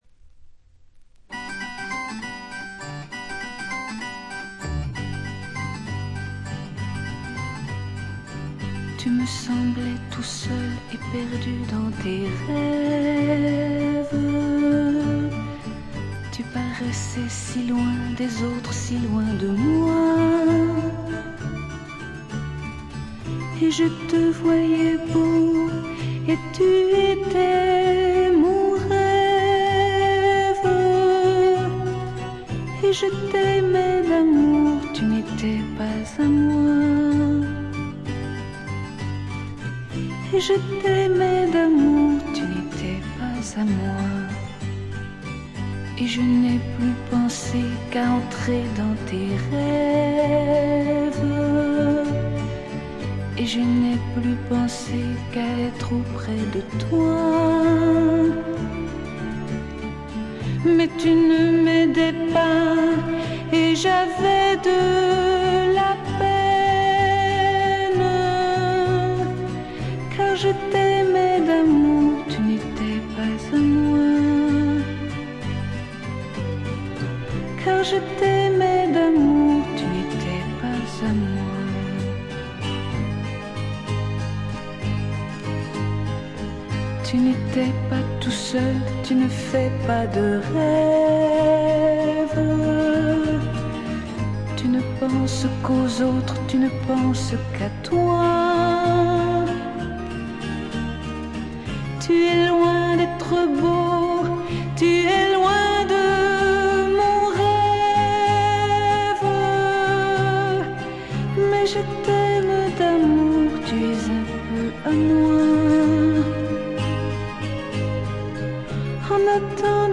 他はほとんどノイズ感なしで良好に鑑賞できます。
試聴曲は現品からの取り込み音源です。